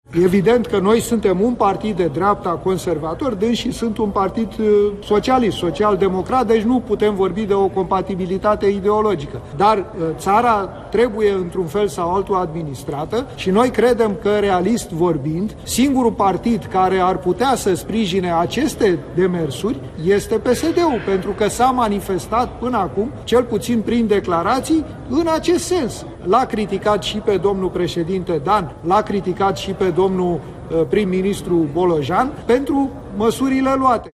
Liderul senatorilor AUR, Petrișor Peiu, într-o conferință de presă: Țara trebuie  administrată, iar noi credem că singurul partid care ar putea să sprijine aceste demersuri este PSD-ul